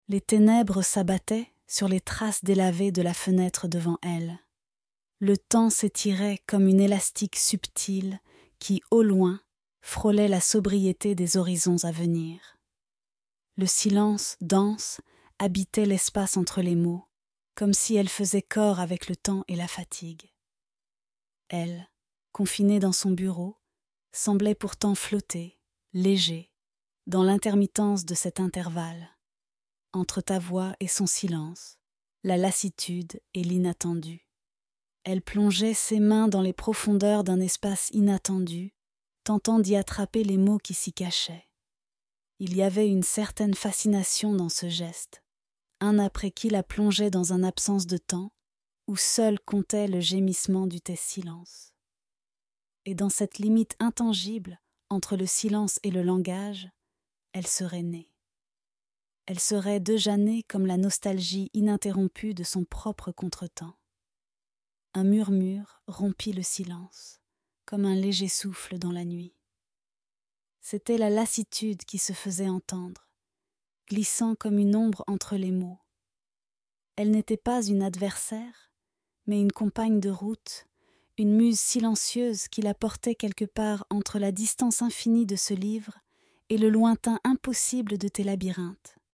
*Murmudõ0delå: Le murmure de l’oubli d’une forêt qui se tait en crescendo.